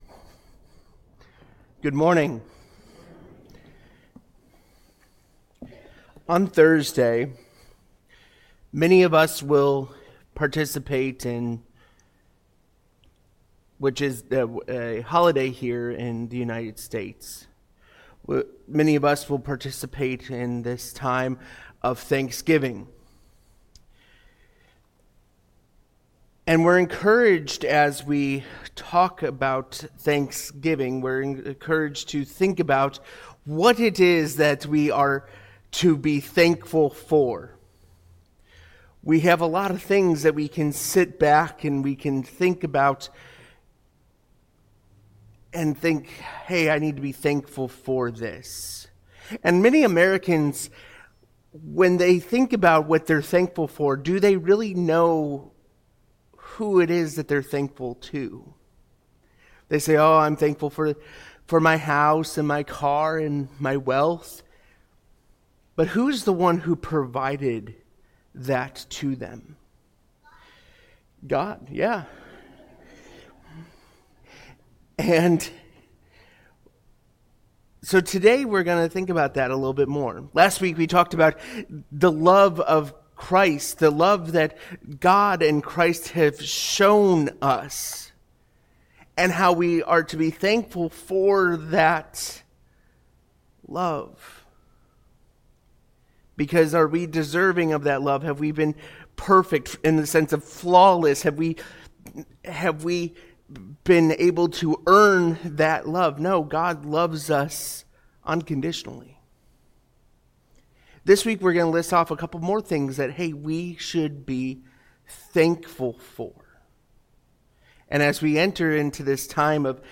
Sunday Sermons What Can We Be Thankful For?